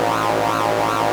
poly_beam_laser_loop.wav